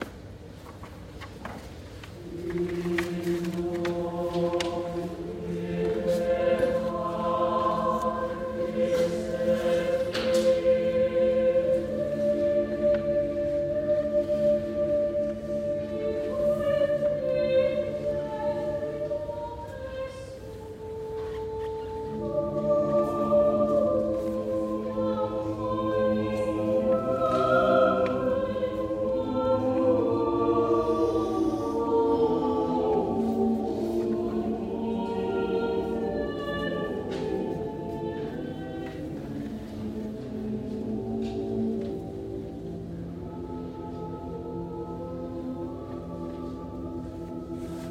Festival Internacional Música y Danza de Granada
Crucero Hospital Real 22 de junio de 2025